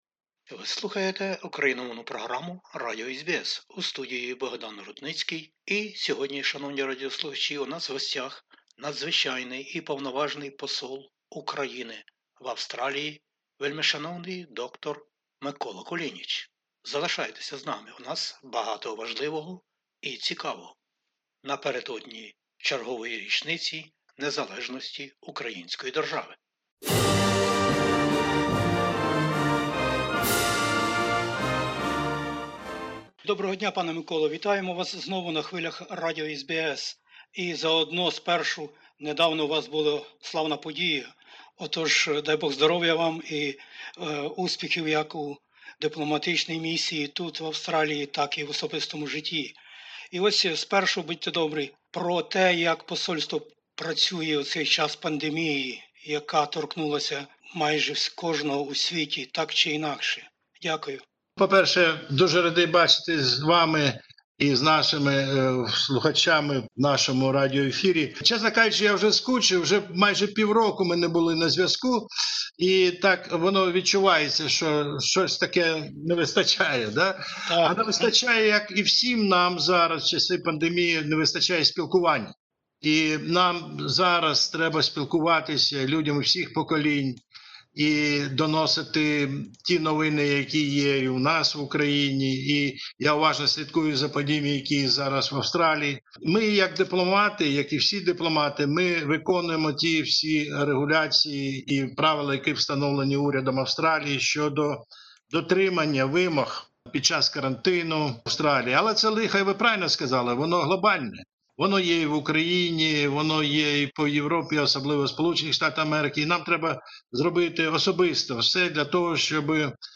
розмовляє із Надзвичайним і Повноважним Послом України в Австралії високодостойним д-ром Миколою Кулінічем. А мова - про взаємини поміж Україною та Австралією, європейський вибір України, роботу Посольства України у час пандемії коронавірусу та співпрацю із українцями та їх нащадками на 5-му континенті, зовнішню політику Української держави та погляд на білоруські події.